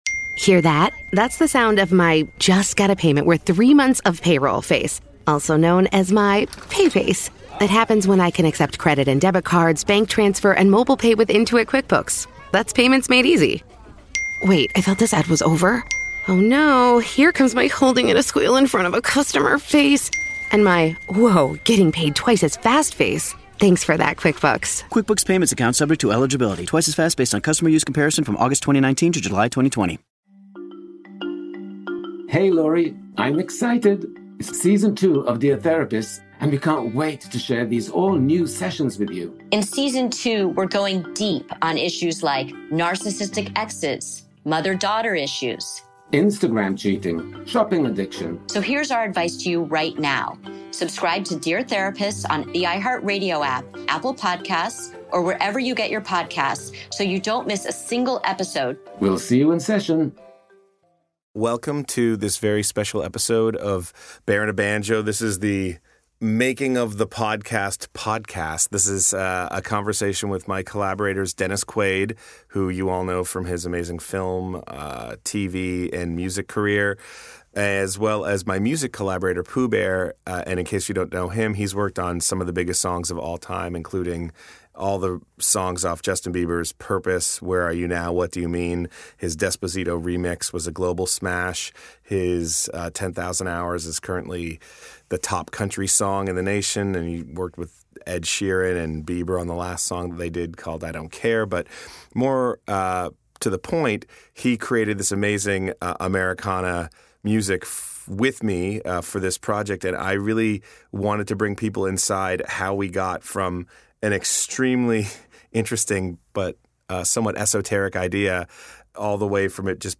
Valentine's Day In Hell is a horror comedy musical podcast that twists the knife on love; satirizing social media influencers, hype houses, and classic teen horror flicks..